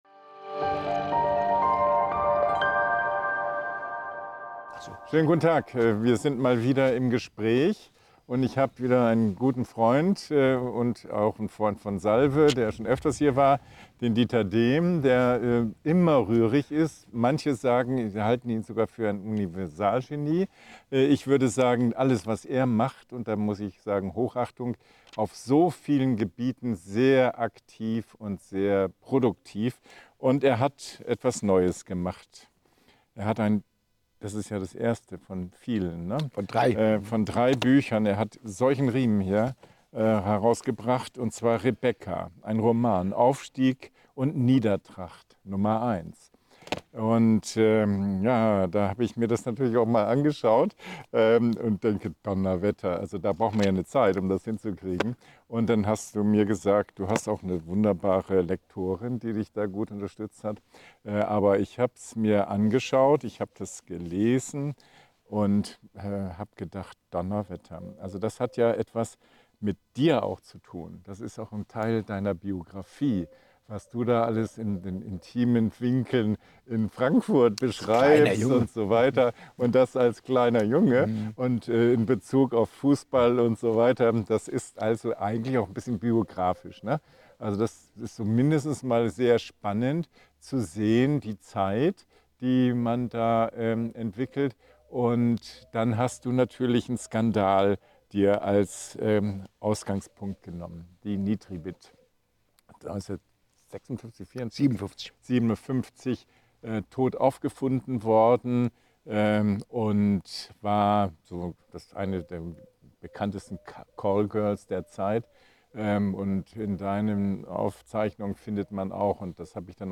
Im Gespr�ch